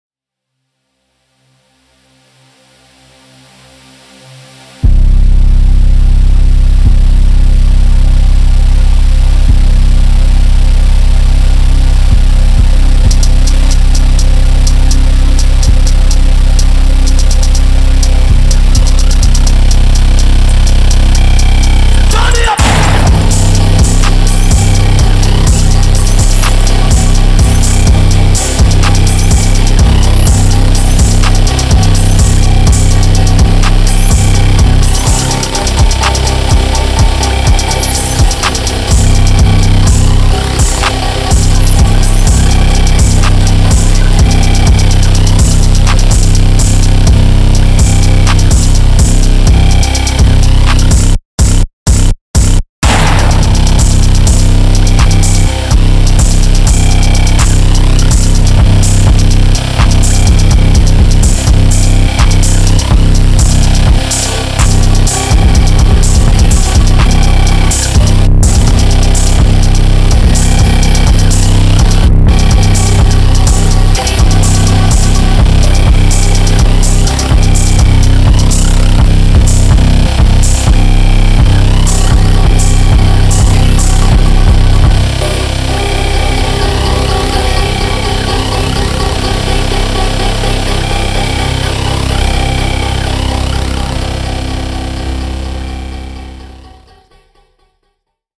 Жанр: Opium
rage Мощный 125 BPM